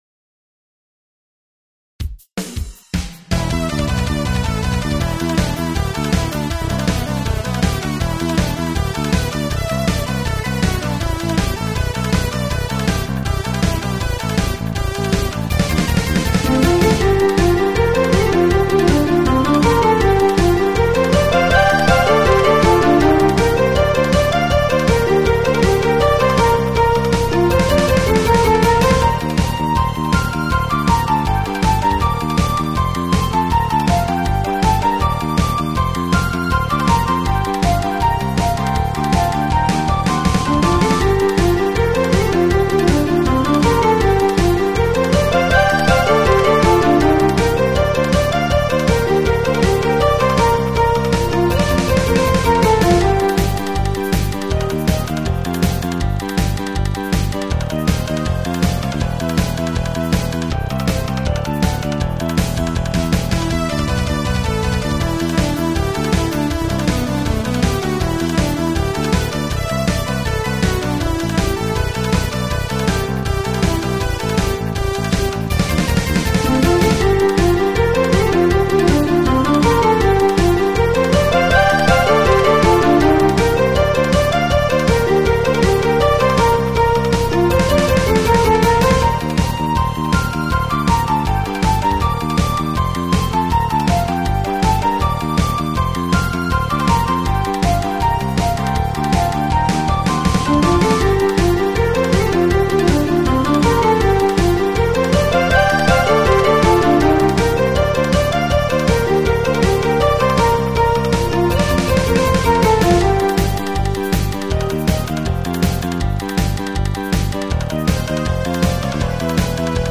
アップロードサイズ制限の都合上mp3の劣化が激しいのはご愛嬌
耳コピ